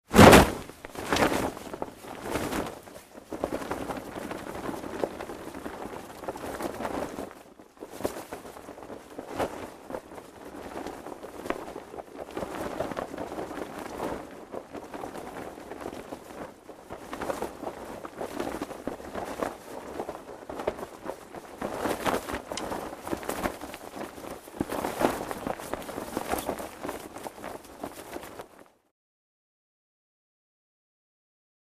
Cloth; Parachute Opening And Flapping.